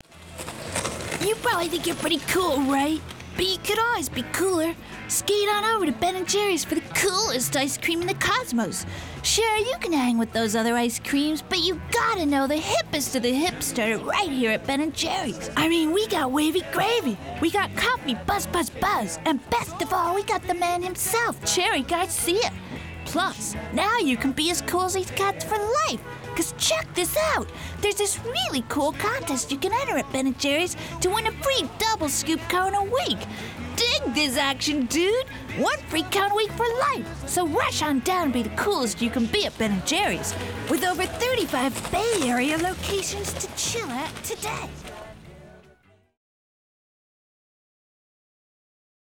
Ben 'N Jerry's (Character Voice)